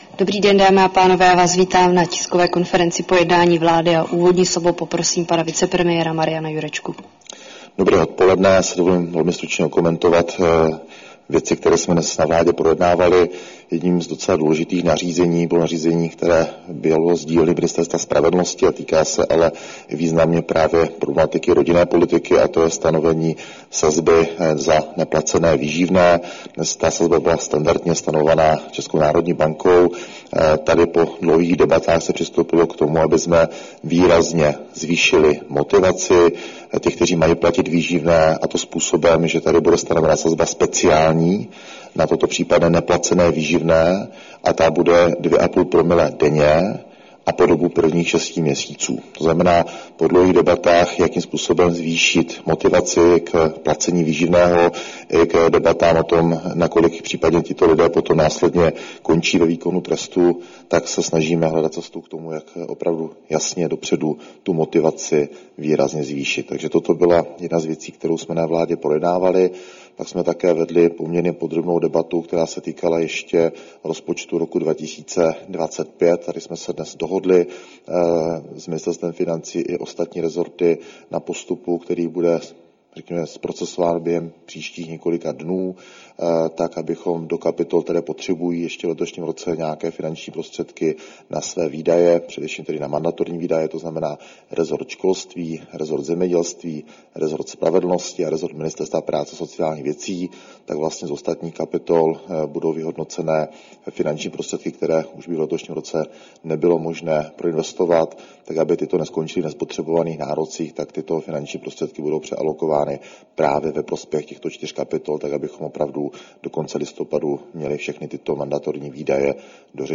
Tisková konference po jednání vlády, 19. listopadu 2025